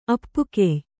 ♪ appukey